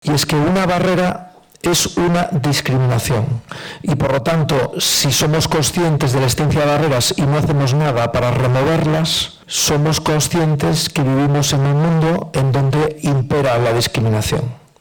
Fundación ONCE acogió, el pasado 7 de noviembre, un encuentro organizado por ‘AccessibleEU’ para impulsar el diálogo entre industria, administraciones públicas y sociedad civil con el objetivo de mejorar la accesibilidad.